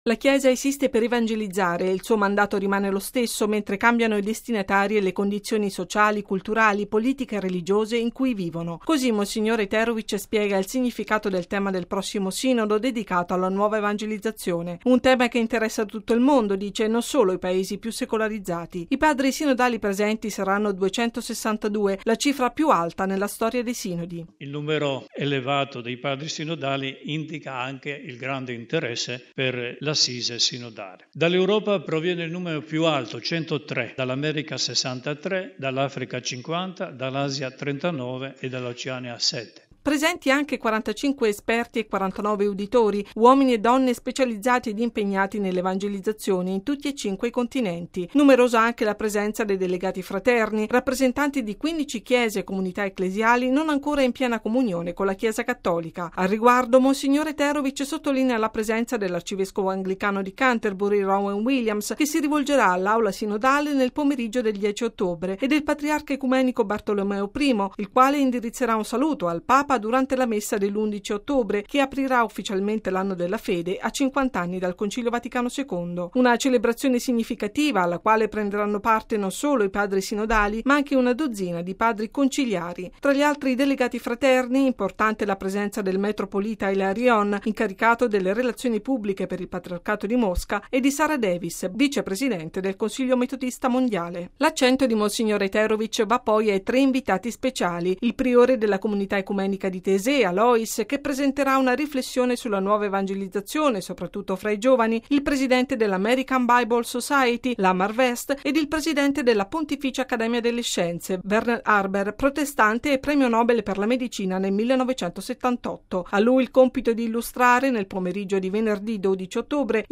◊   “La nuova evangelizzazione per la trasmissione della fede cristiana”: su questo tema si svolgerà in Vaticano, dal 7 al 28 ottobre, il 13.mo Sinodo generale ordinario dei vescovi. L’evento è stato presentato stamani nella Sala stampa vaticana dal segretario generale dell’assise sinodale, l'arivescovo Nikola Eterović.